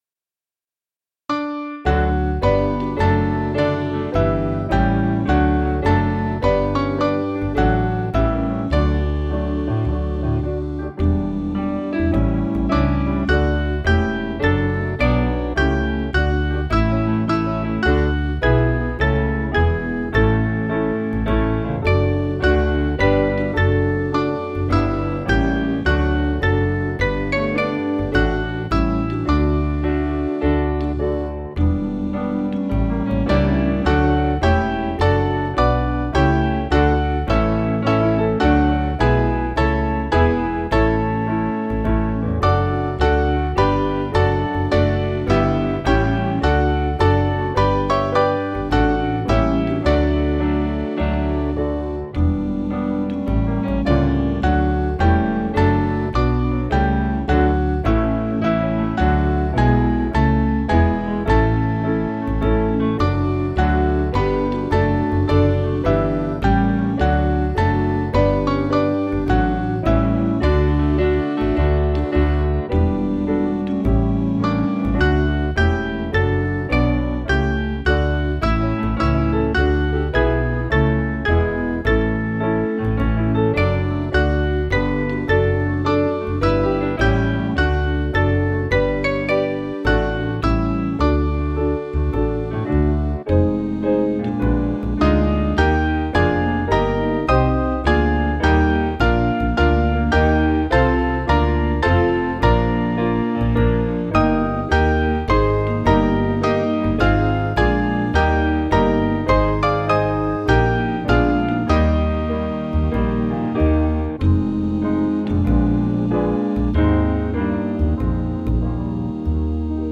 Mainly Piano
(CM)   5/D-Eb 485.6kb